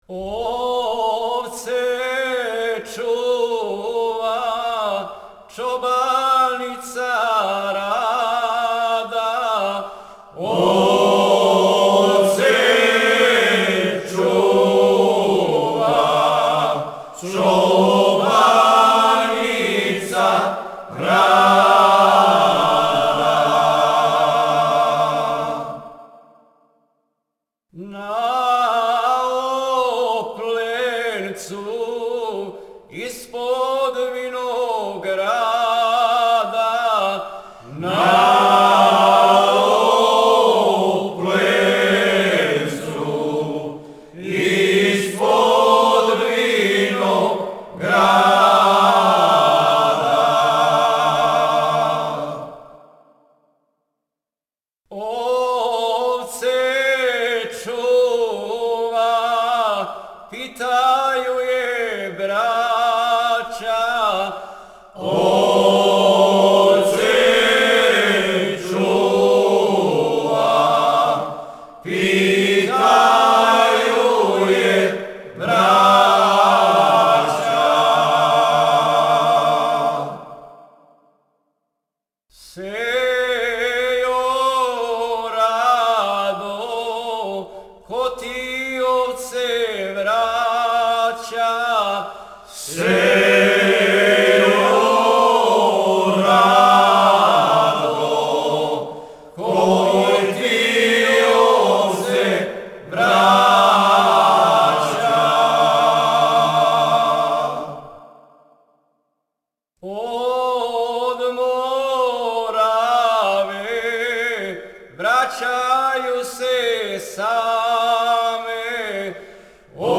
Група "Стеван Книћанин", Кнић
Порекло песме: Шумадија